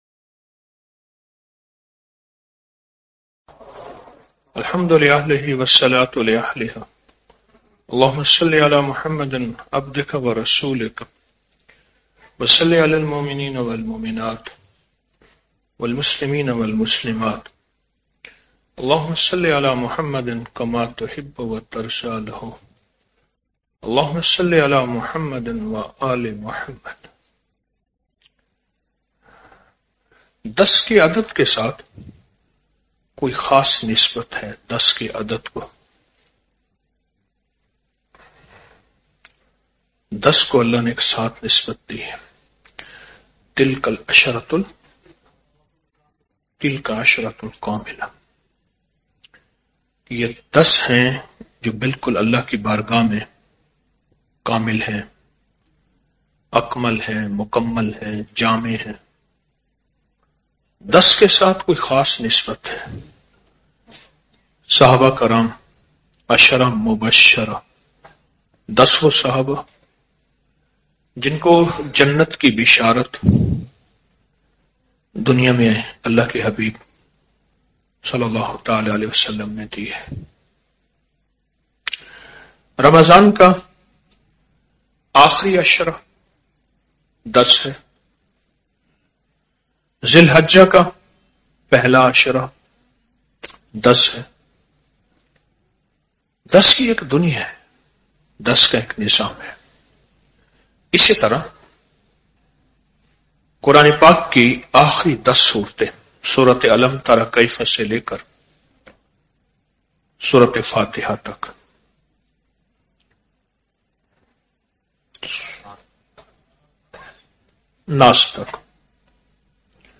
Audio Speech - Shab e Jumma Mehfil - 02 January 2025